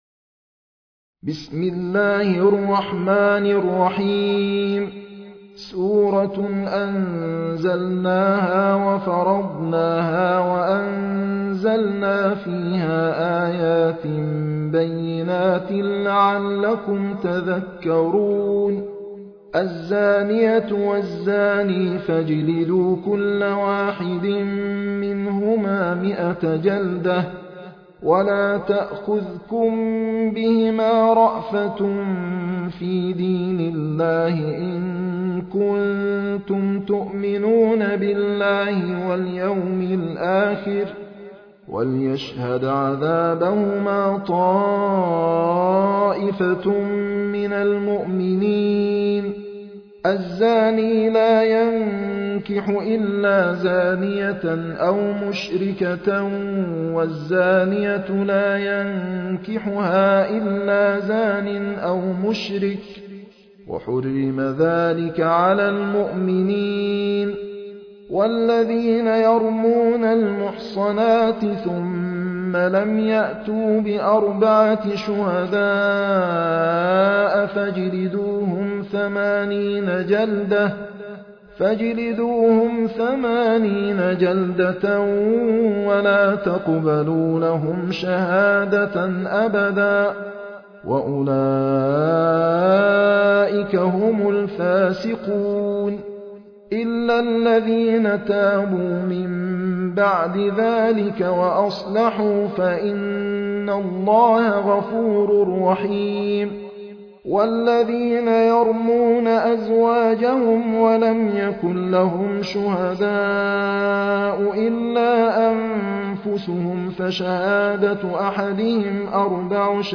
المصحف المرتل - حفص عن عاصم - النور